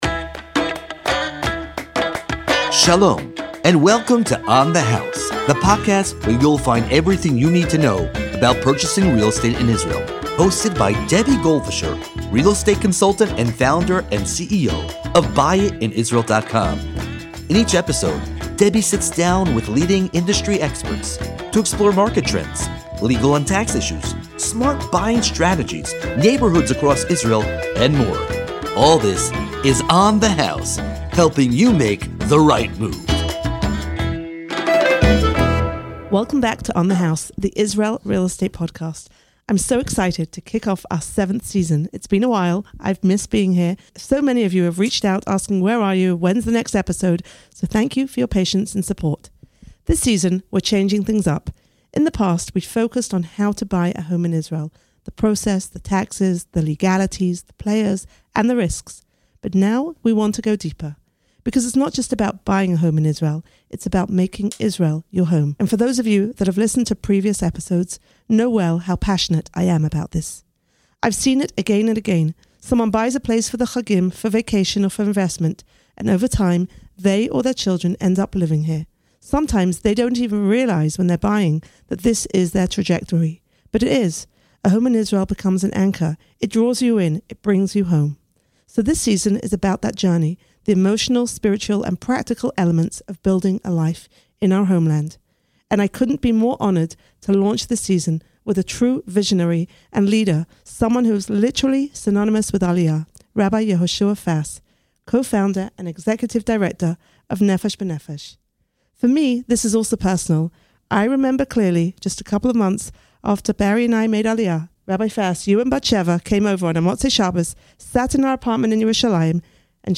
In this inspiring and wide-ranging conversation